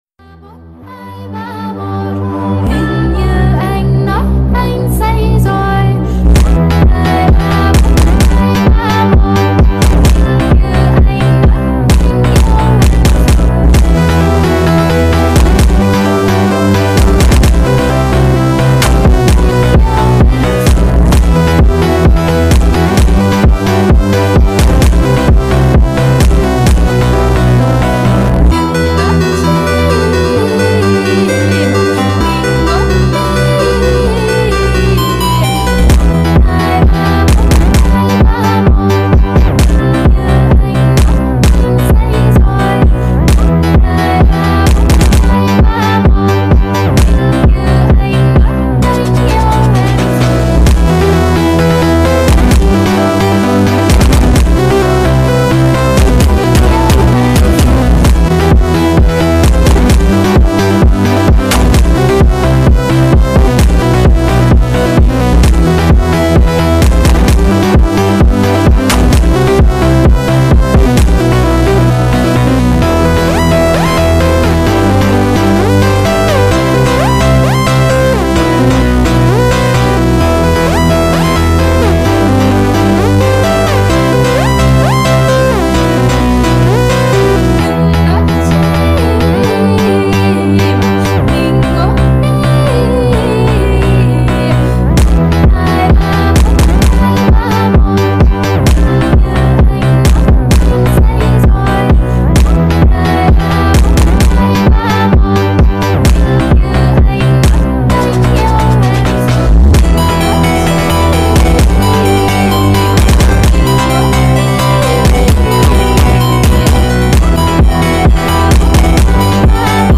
передает атмосферу легкости и непринужденности